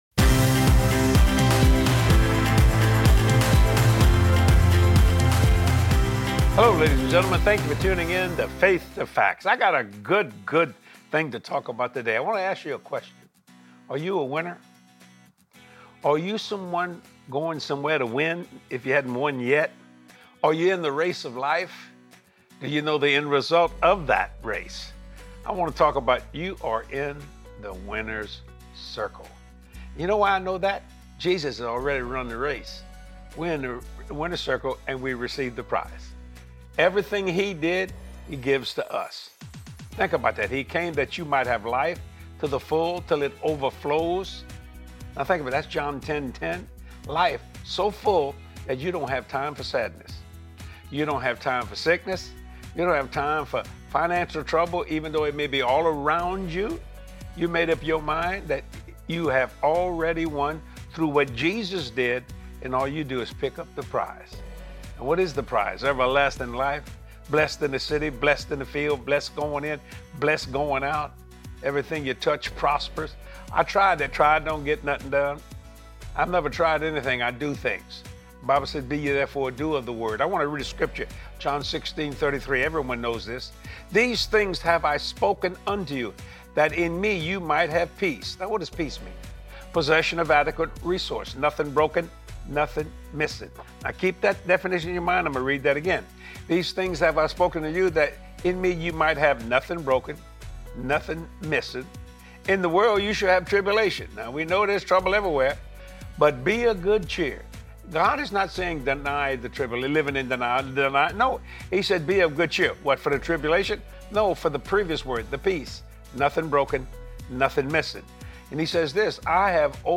You are a winner in Christ Jesus! Watch this anointed teaching from Jesse and discover that in God ALL things are possible!